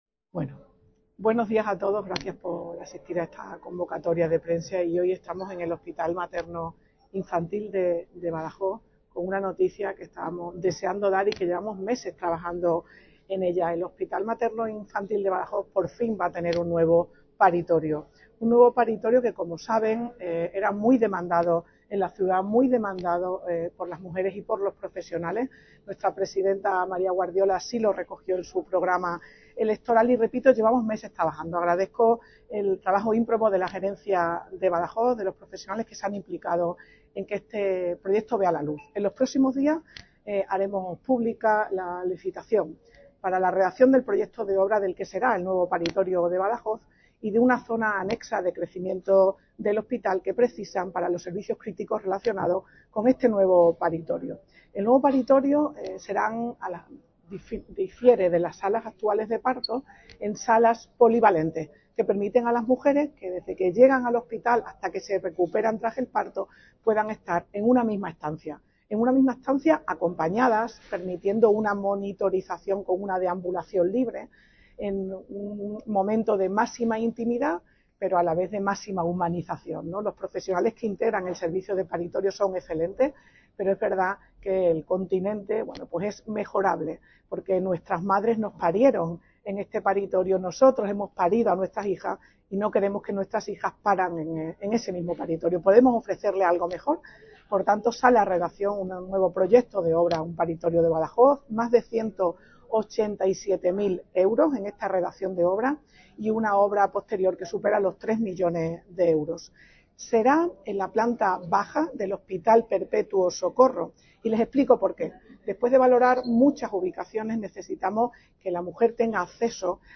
Audio declaraciones consejera